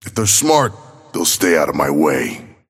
Abrams voice line - If they're smart, they'll stay out of my way.